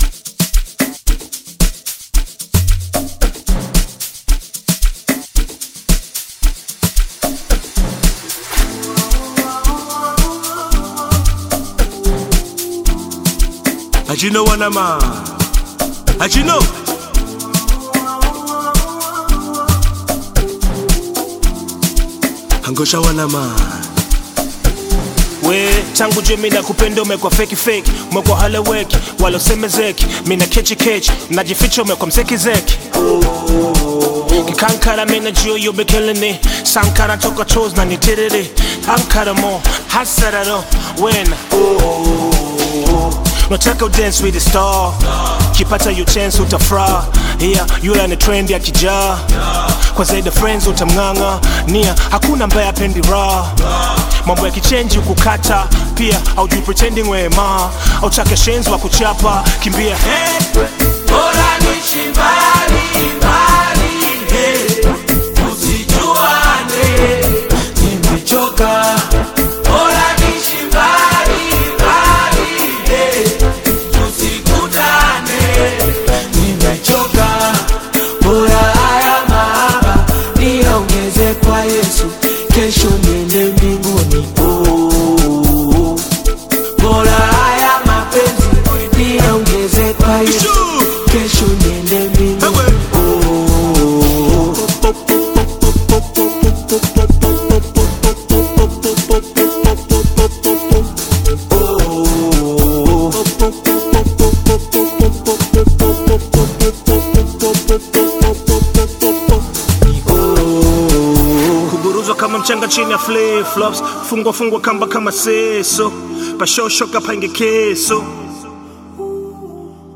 Amapiano